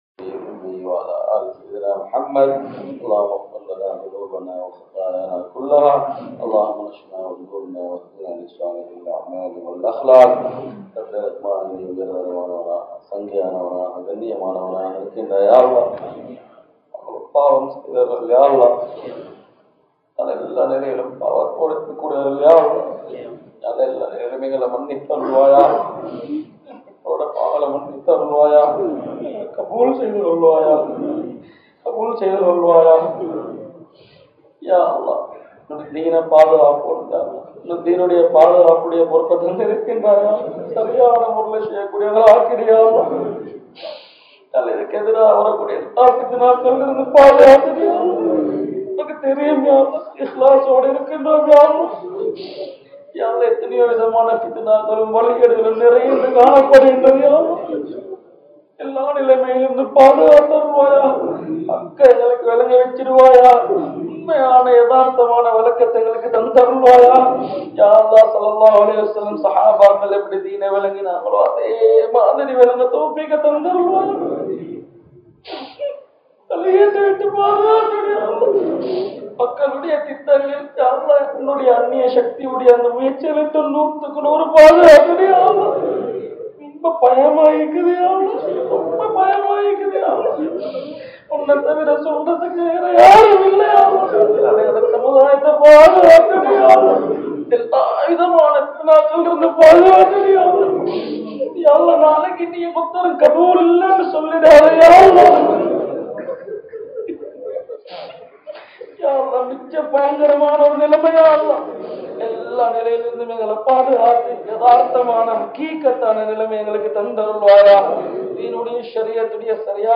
Elamalpotha, Majmaulkareeb Jumuah Masjith